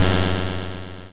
synth12.wav